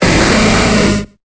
Cri de Smogogo dans Pokémon Épée et Bouclier.